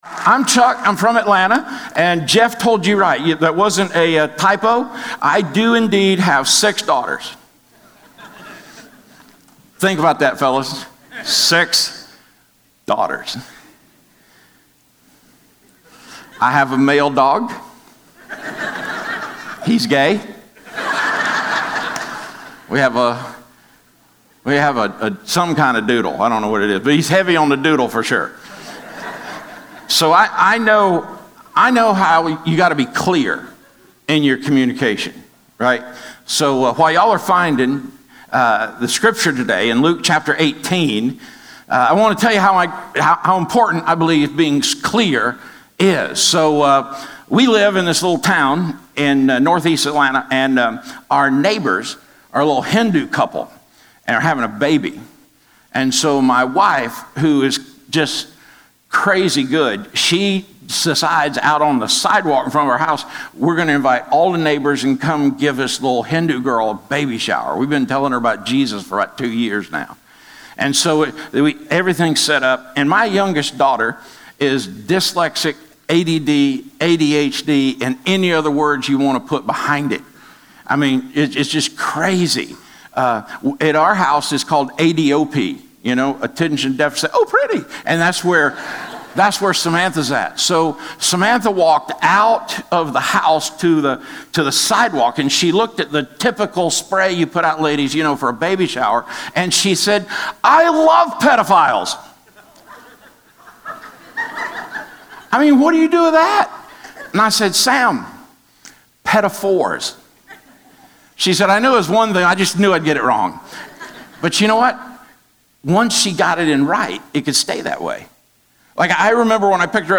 Historical Message Archives The Historical Message Archives includes weekly Sunday morning messages from August 1992 – August 2018.